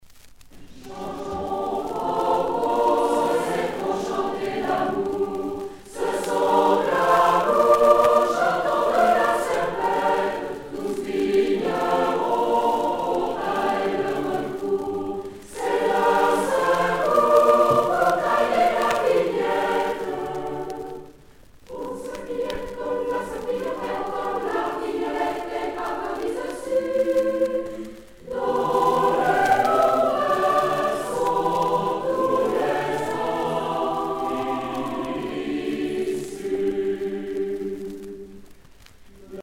Rassemblement des chorales A Coeur Joie